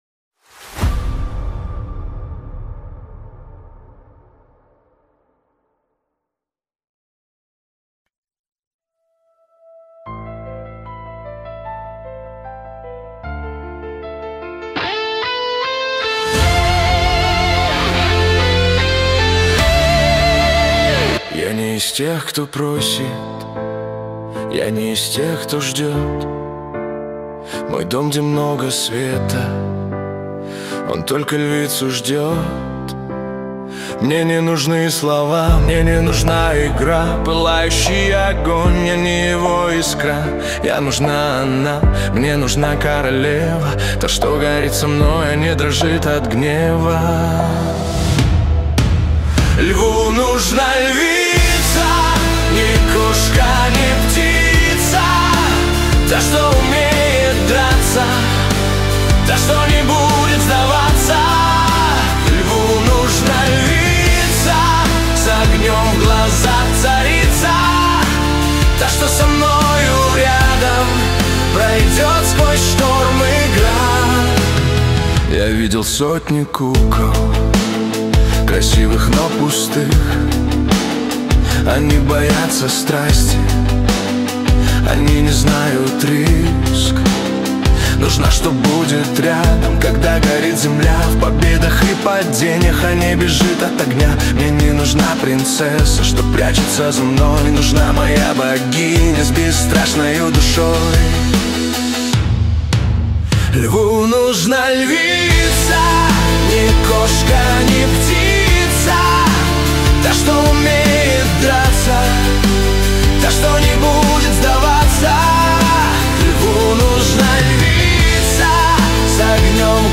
13 декабрь 2025 Русская AI музыка 85 прослушиваний